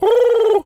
pigeon_call_angry_12.wav